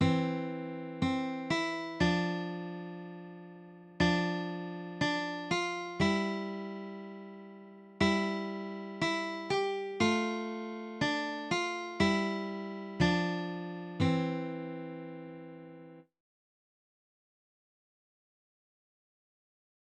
Beginner Guitar Solo